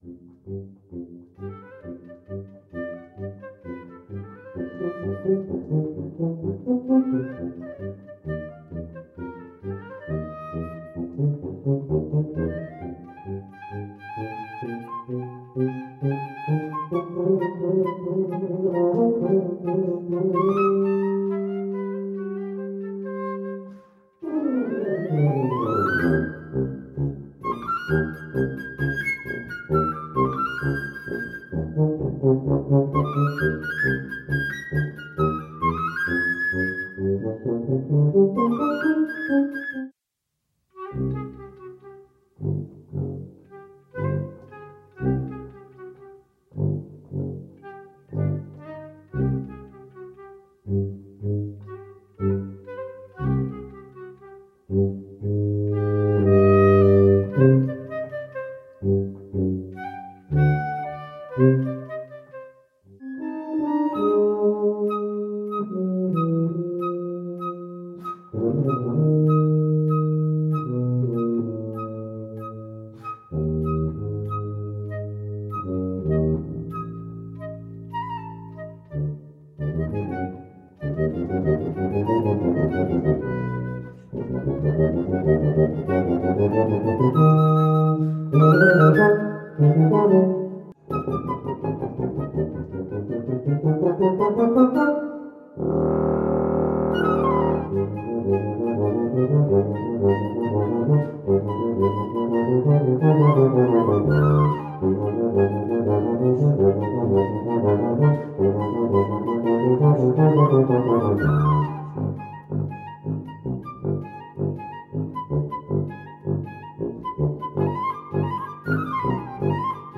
Voicing: Tuba/Flute